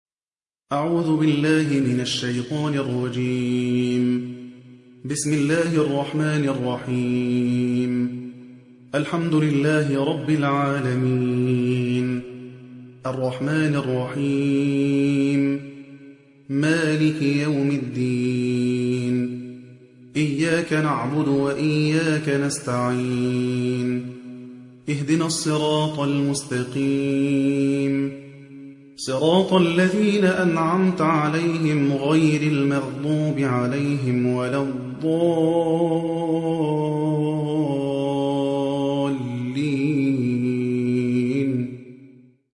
(رواية حفص)